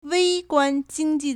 微观经济 (微觀经济) wēiguān jīngjì
wei1guan1jin1ji4.mp3